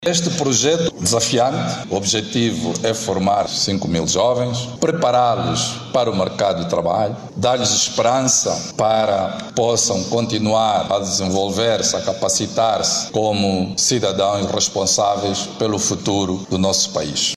Segundo o Ministro dos Recursos Minerais, Petróleo e Gás, Diamantino Azevedo, o projecto Kuma visa preparar os jovens para o mercado de trabalho.